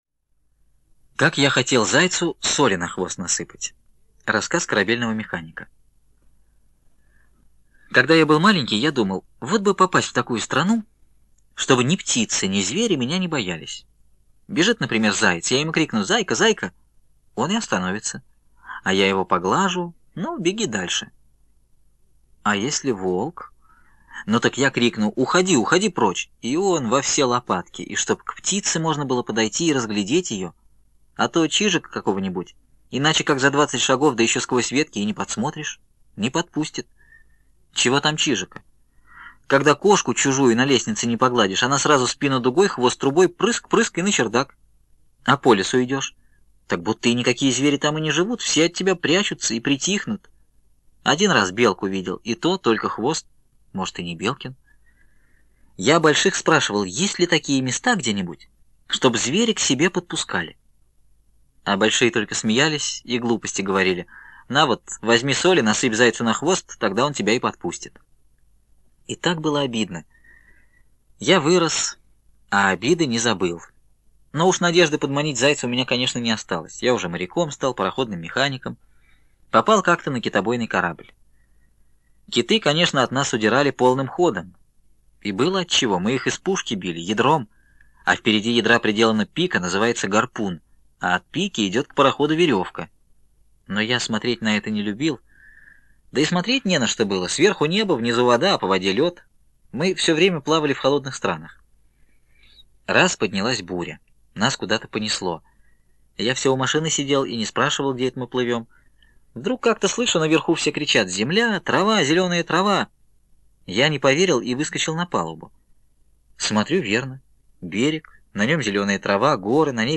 Как я хотел зайцу соли на хвост насыпать - аудио рассказ Бианки В.